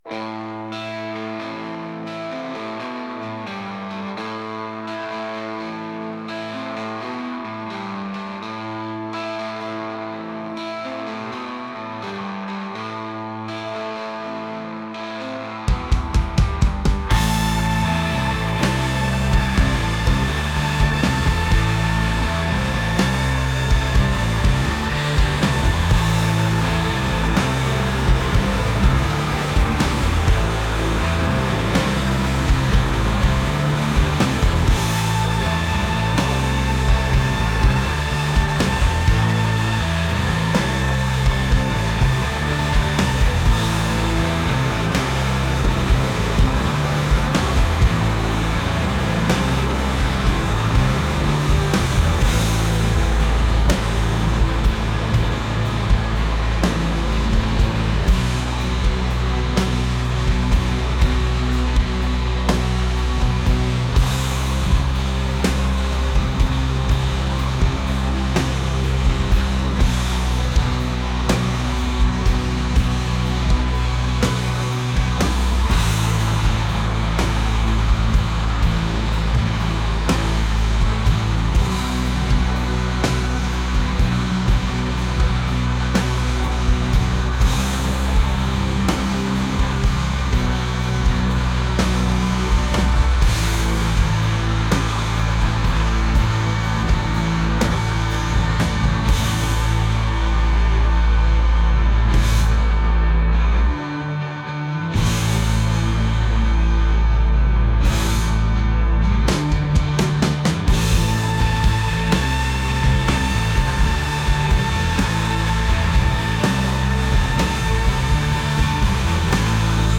rock | ambient